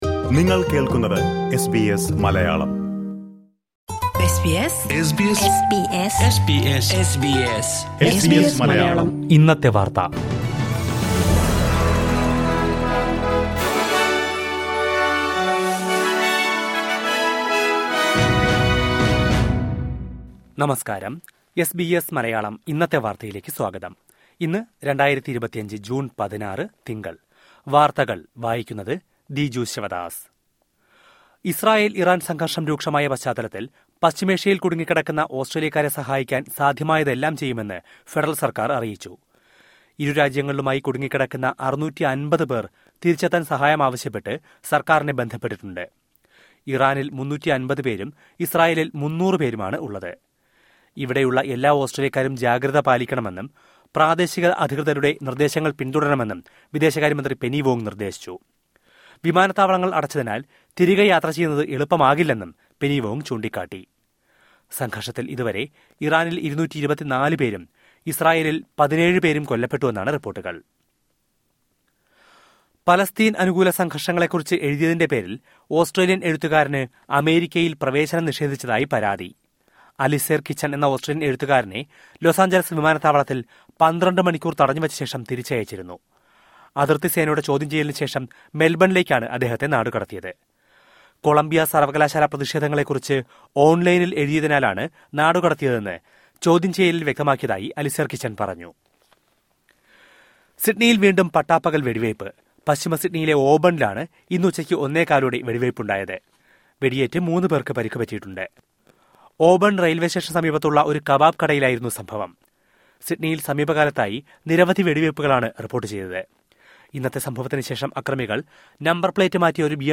2025 ജൂണ്‍ 16ലെ ഓസ്‌ട്രേലിയയിലെ ഏറ്റവും പ്രധാന വാര്‍ത്തകള്‍ കേള്‍ക്കാം...